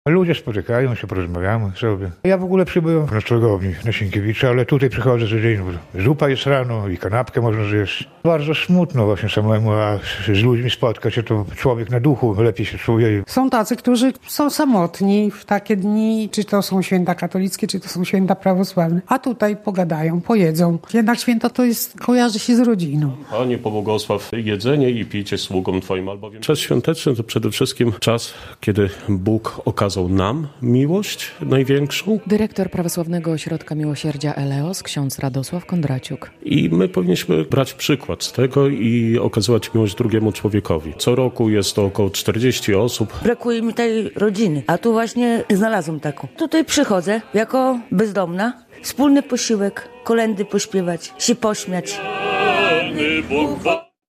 Ubodzy i bezdomni wzięli udział w Wigilii zorganizowanej przez Prawosławny Ośrodek Miłosierdzia Eleos - relacja